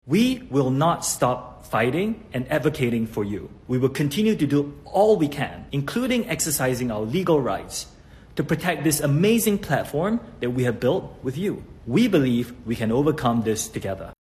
The boss of TikTok, Shou Chew, is vowing to fight back, and had this message for users: